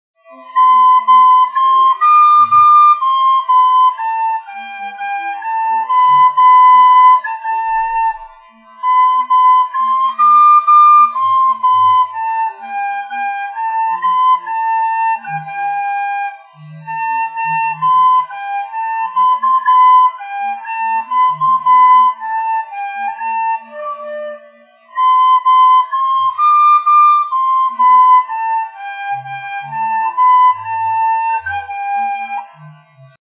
Categories: Irish Tin Whistle
here is an attempt by me to play the tune for Ode to Joy (music lovers you might not want to hear this ;-) )